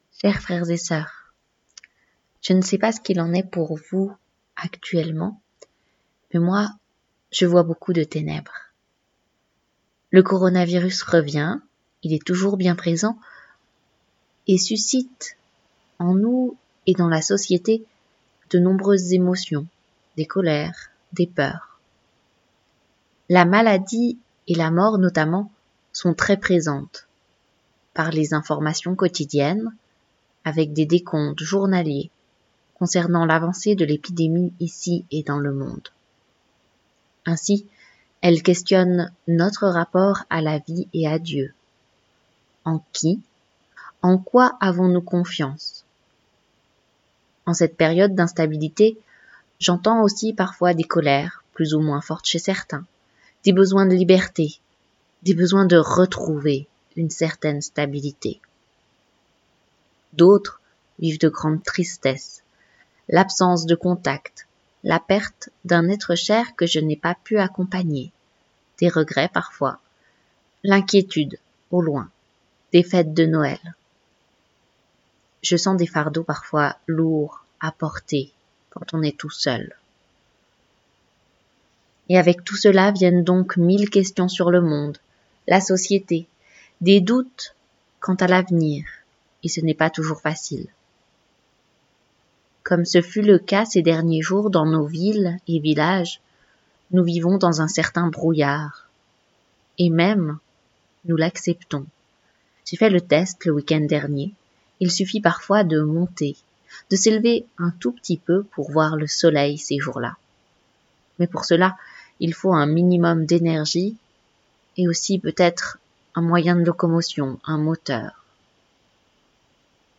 Message
Le message est aussi disponible en version audio